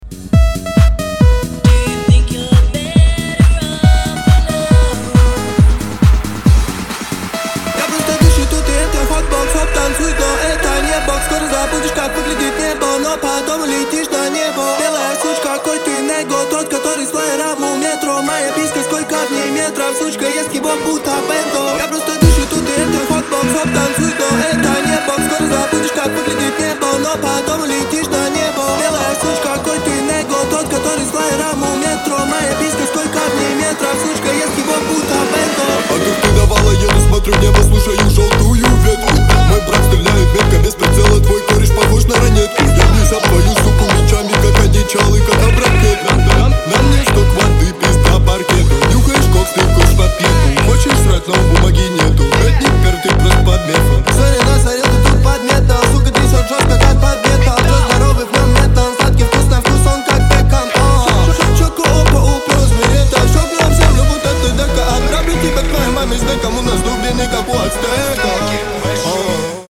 • Качество: 320, Stereo
мужской голос
мощные
русский рэп
Electronic
Mashup
techno
Энергичный mashup из техно и рэпа